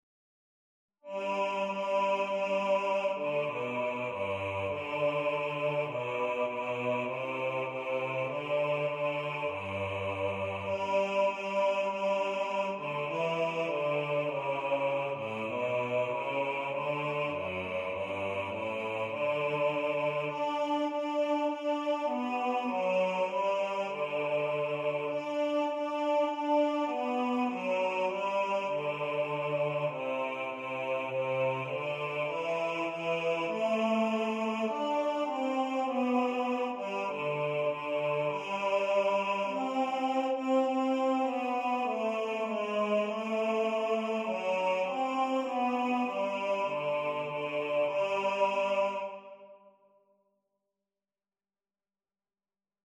Hark! The Herald Angels Sing – Bass | Ipswich Hospital Community Choir
Hark-The-Herald-Angels-Sing-Bass.mp3